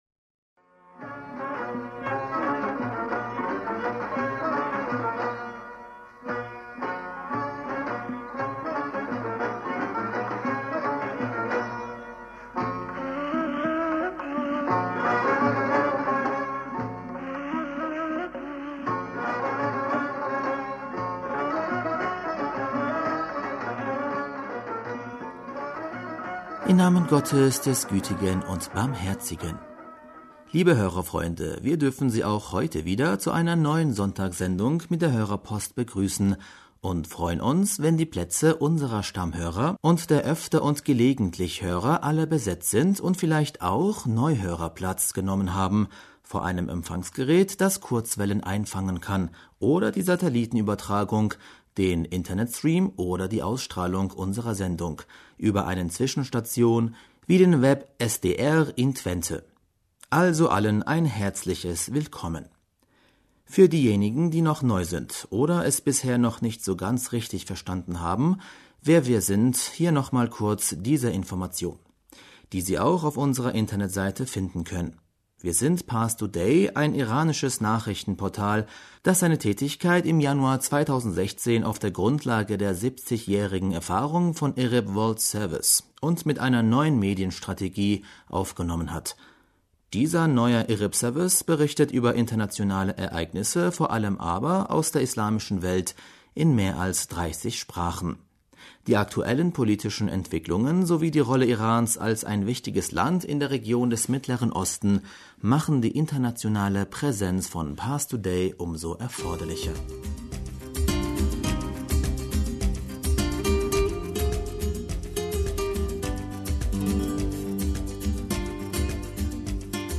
Hörerpostsendung am 10.März 2019 - Bismillaher rahmaner rahim - Liebe Hörerfreunde,